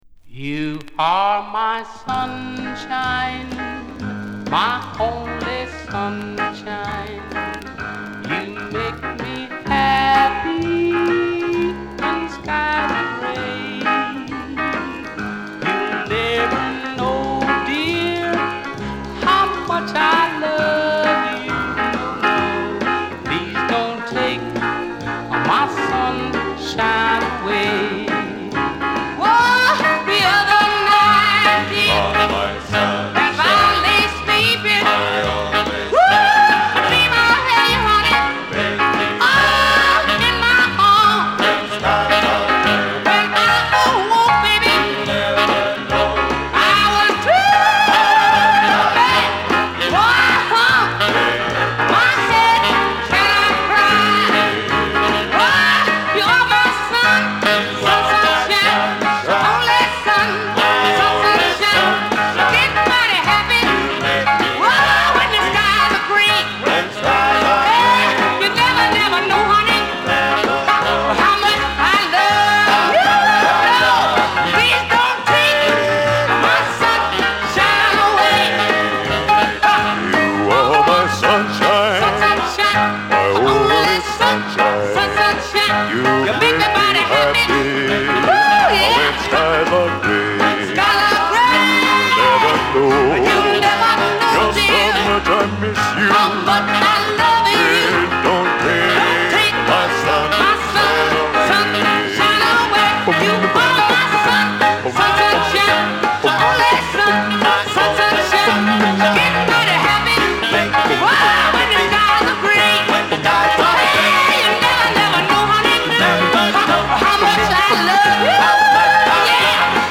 従来通りの張りのあるヴォーカルに疾走感たっぷりの演奏。痛快爽快。
• R&B / BLUES / DOO WOP / BLACK ROCKER